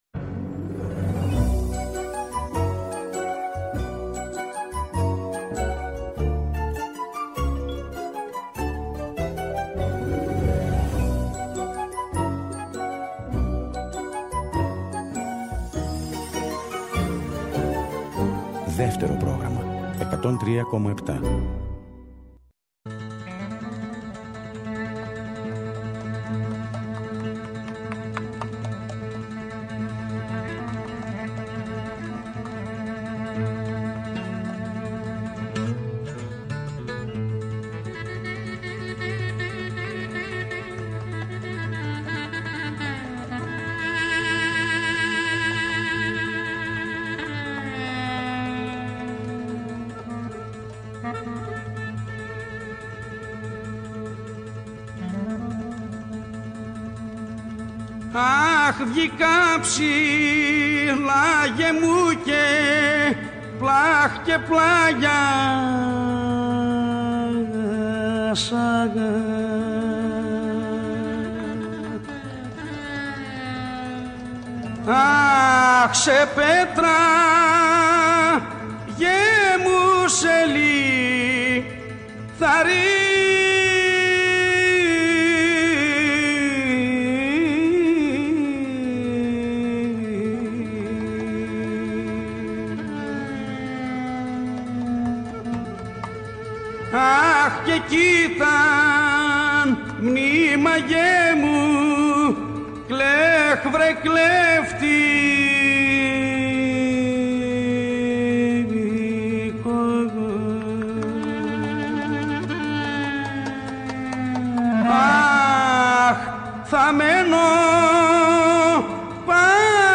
Η παραδοσιακή μας μουσική, το δημοτικό τραγούδι, οι αγαπημένοι μας δημιουργοί από το παρελθόν, αλλά και η νεότερη δραστήρια γενιά των μουσικών, συνθέτουν ένα ωριαίο μουσικό παραδοσιακό γαϊτανάκι.